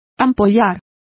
Complete with pronunciation of the translation of blistered.